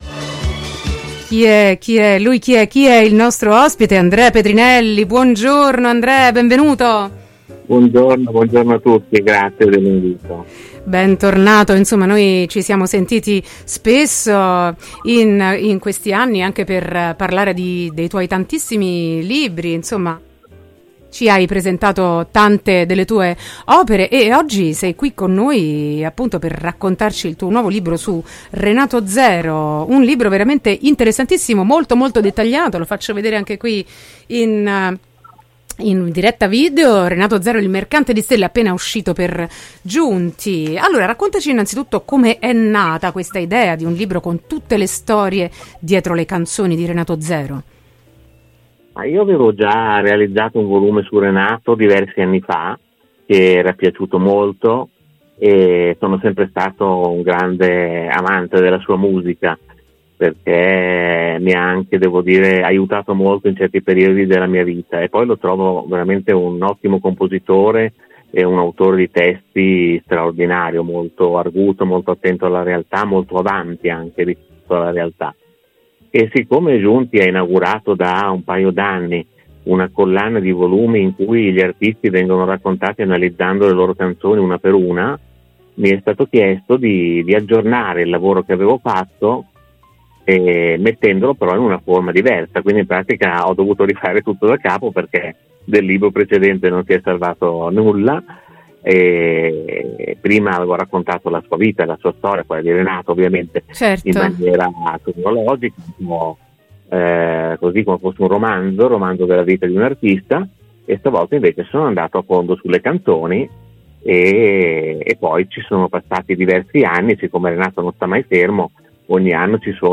I brani ascoltati e commentati nel corso dell’intervista